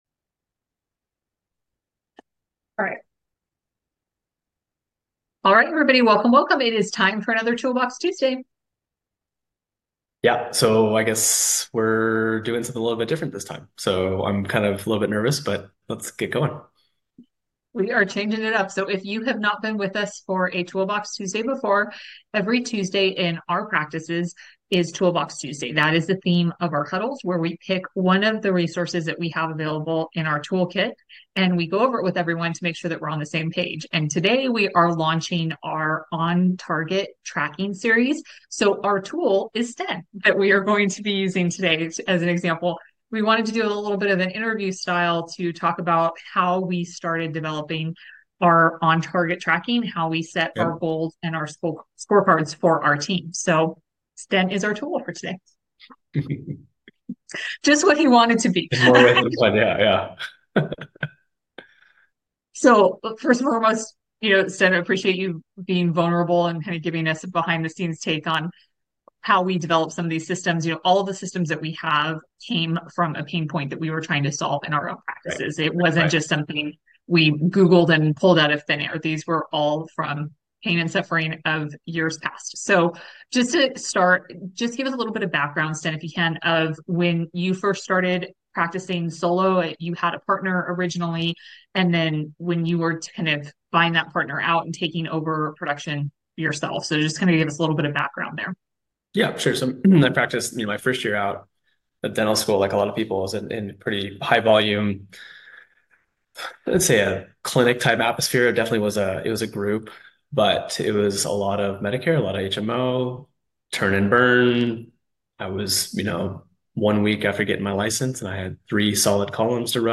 Episode 22: Toolbox Tuesday-Interview